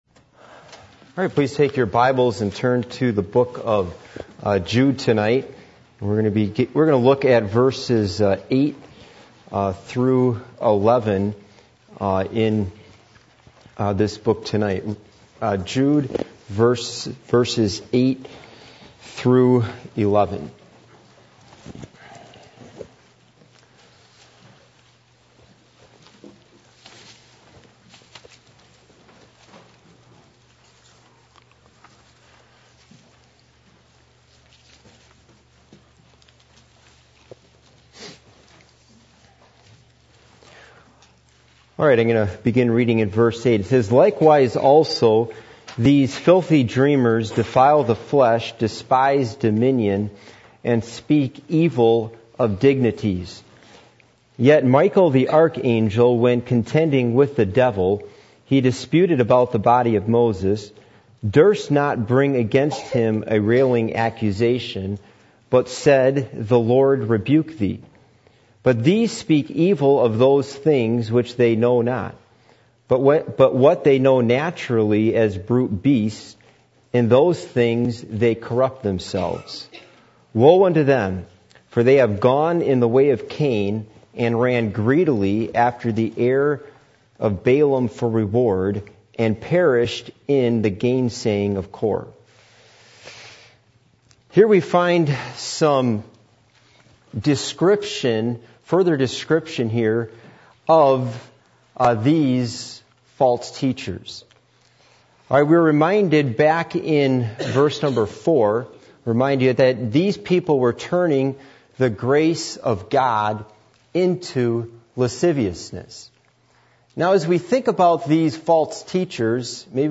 Jude 1:8-11 Service Type: Midweek Meeting %todo_render% « The Judgment of God